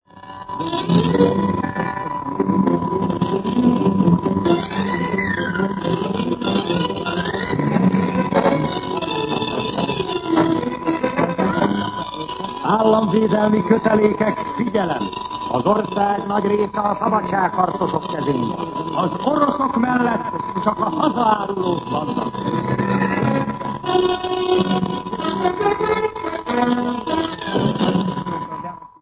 Jelmondatok 1956. október 27. 21:25 ● 00:28 ► Meghallgatom Műsor letöltése MP3 00:00 00:00 A műsor leirata Szignál Államvédelmi kötelékek, figyelem!